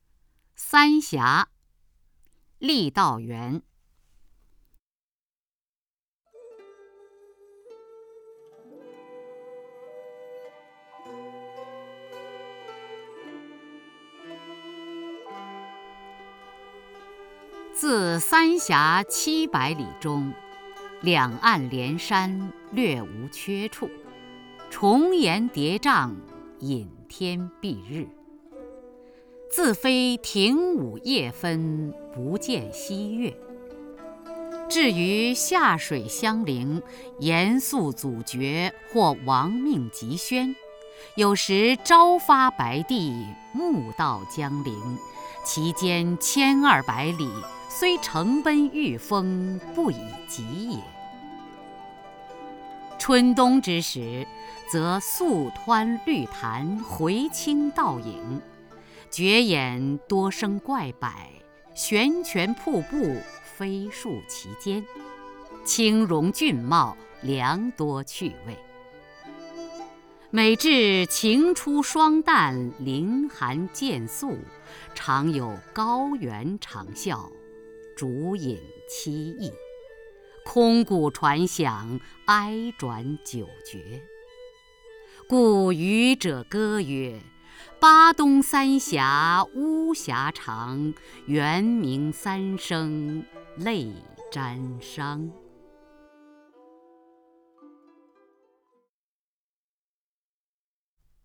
雅坤朗诵：《三峡》(（北魏）郦道元，选自《水经注·江水》)
名家朗诵欣赏 雅坤 目录